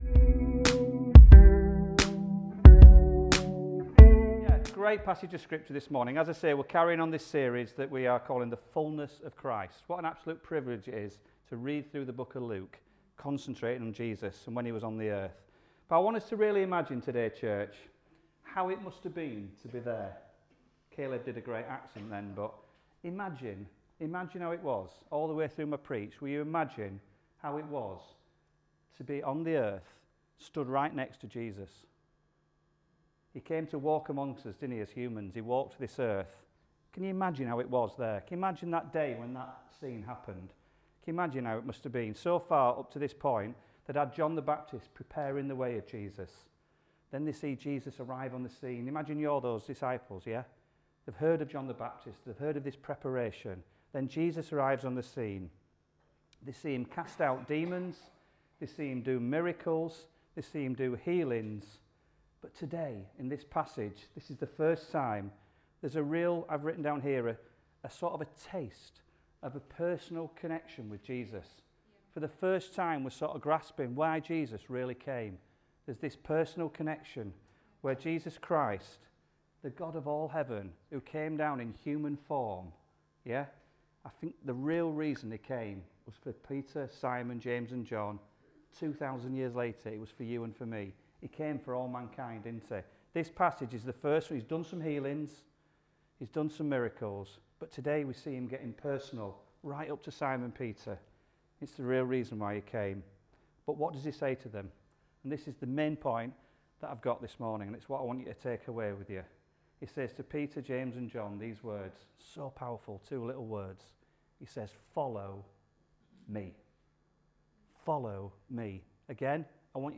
Sunday Messages The Fullness Of Christ